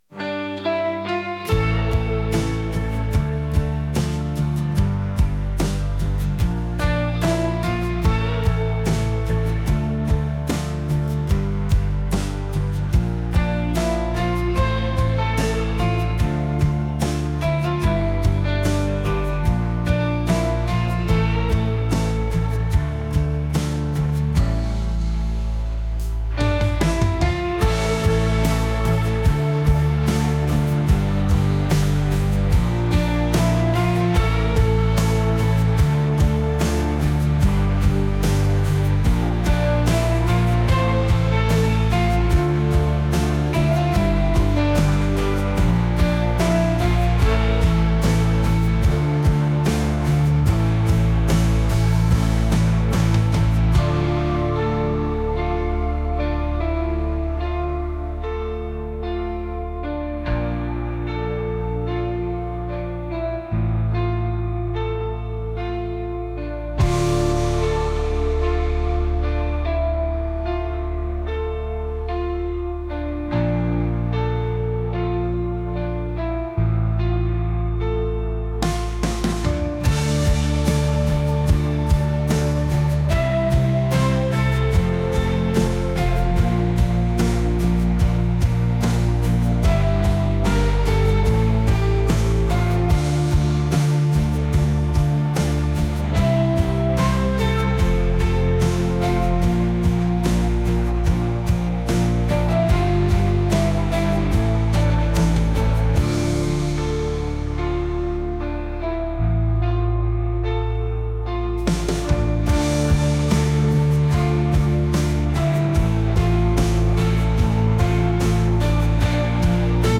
pop | ambient | cinematic